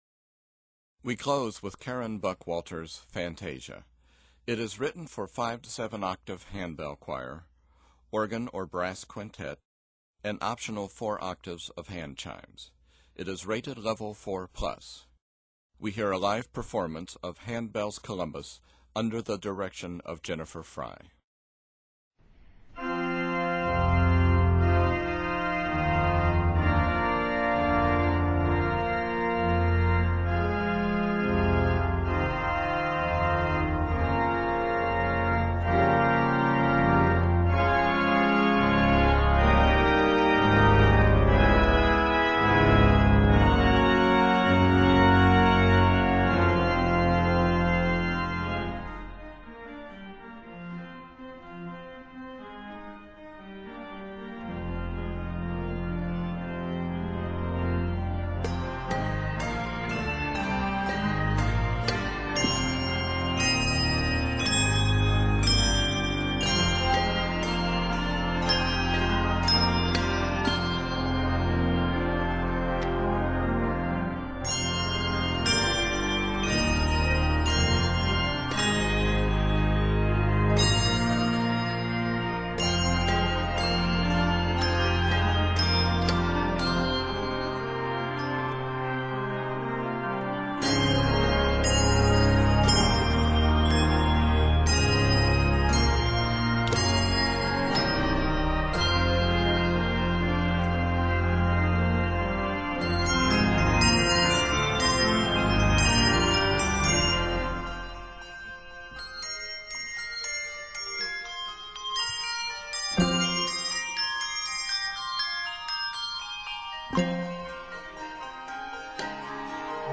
is primarily set in f minor and F Major.
N/A Octaves: 5-7 Level